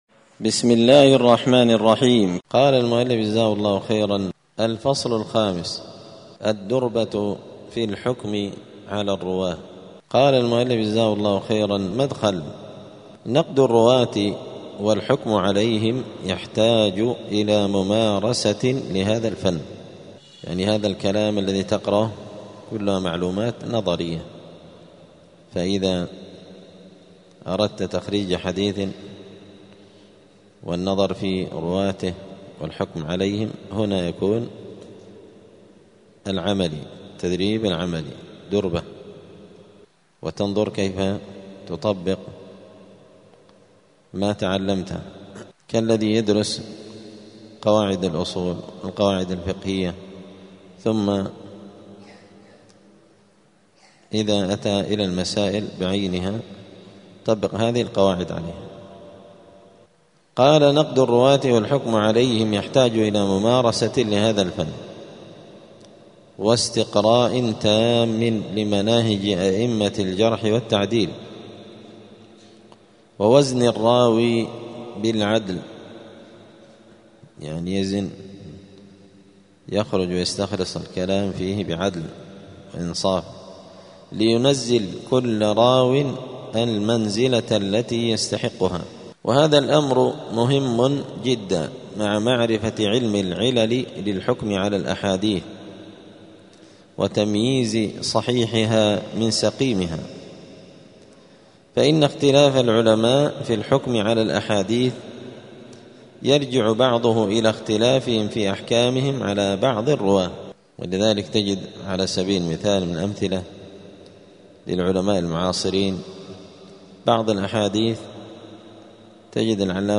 الجمعة 22 شعبان 1446 هــــ | الدروس، المحرر في الجرح والتعديل، دروس الحديث وعلومه | شارك بتعليقك | 23 المشاهدات
دار الحديث السلفية بمسجد الفرقان قشن المهرة اليمن